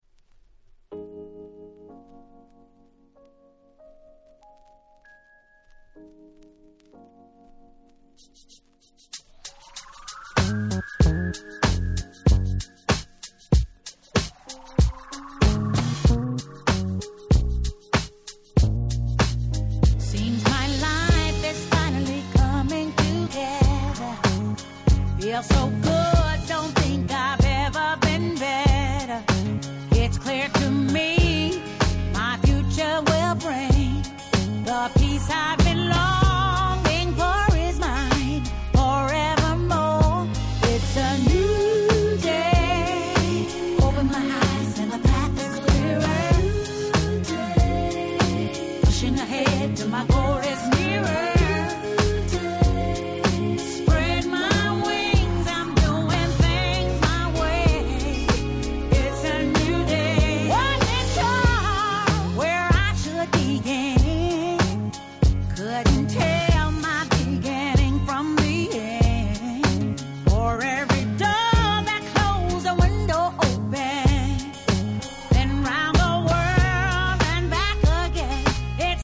04' Nice R&B/Neo Soul.
良い意味で時代の波に流されないSweetでGroovyな曲調、彼女のVocalもバッチリ健在！！
言う事無しの素晴らしいNeo Soul/現行Soulに仕上がっております。